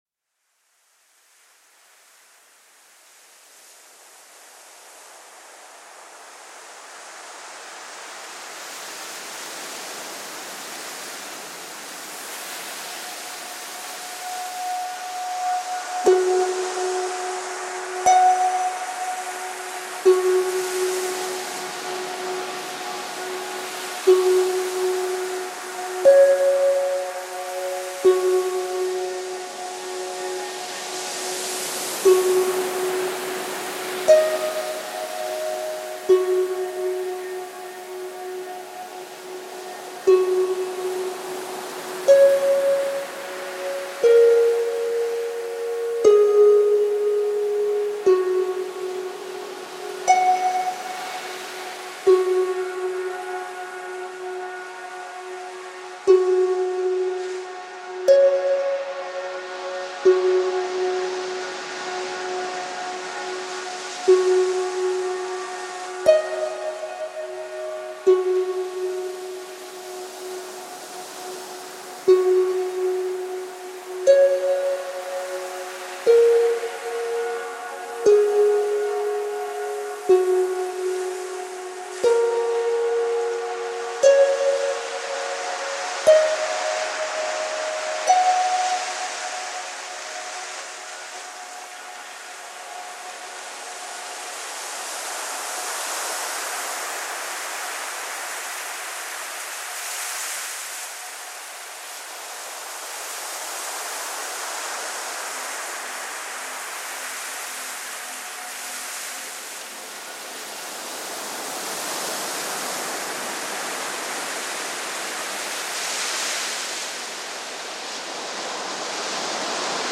O som da sessão vai aparecendo lentamente.